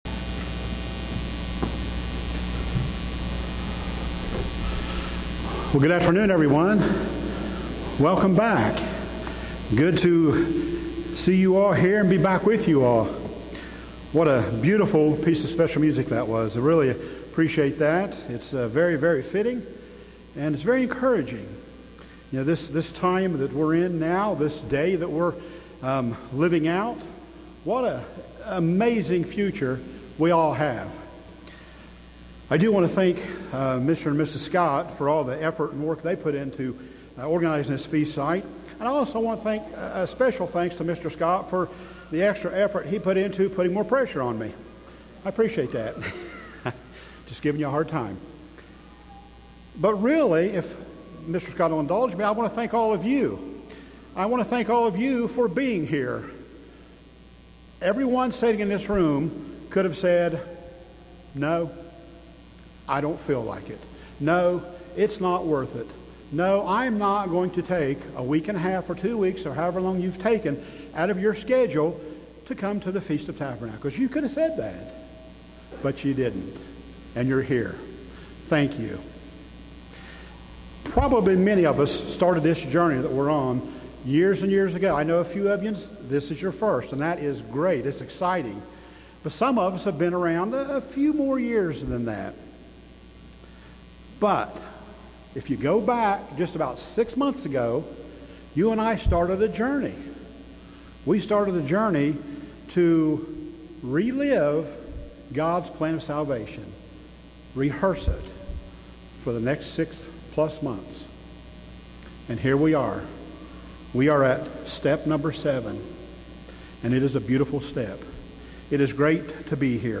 This sermon was given at the Gatlinburg, Tennessee 2023 Feast site.